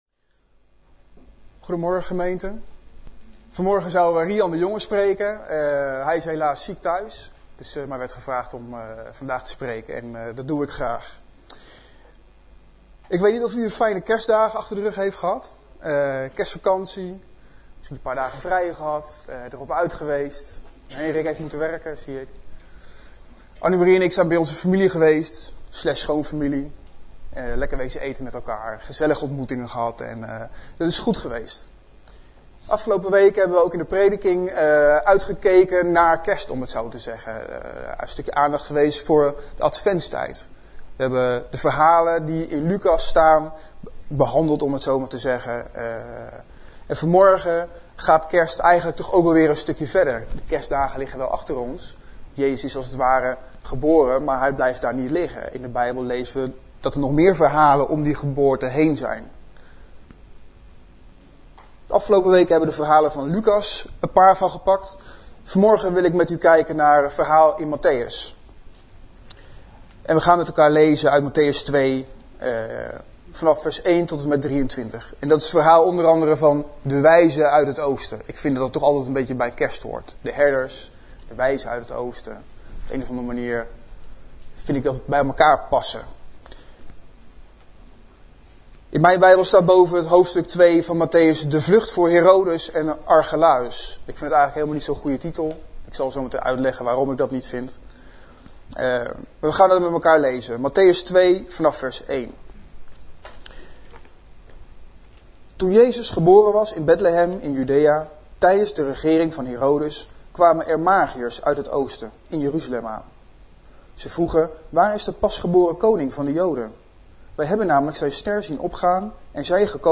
Overzicht van preken van Preken op Christengemeente Bethel